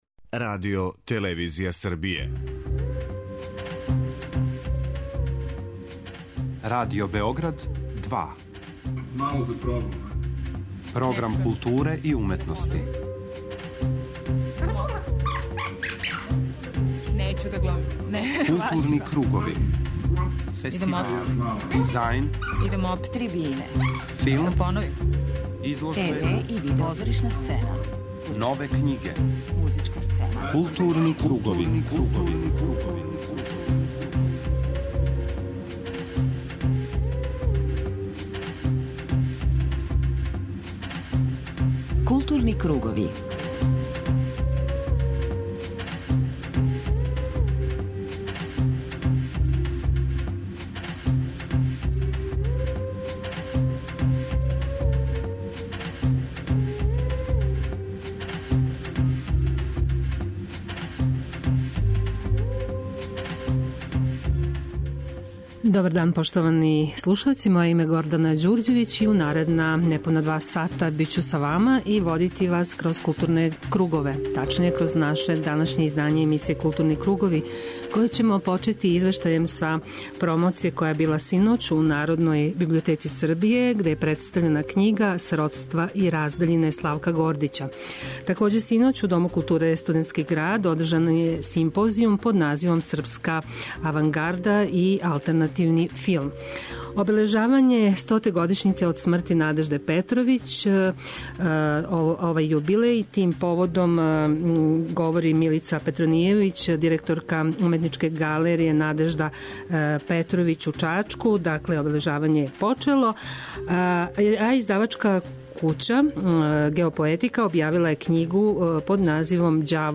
преузми : 51.80 MB Културни кругови Autor: Група аутора Централна културно-уметничка емисија Радио Београда 2.